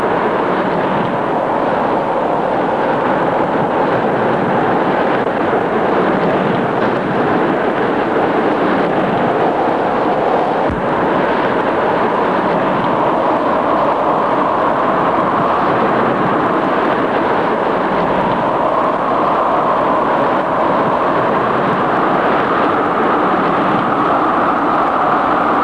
Wind And Rain Sound Effect
Download a high-quality wind and rain sound effect.
wind-and-rain-1.mp3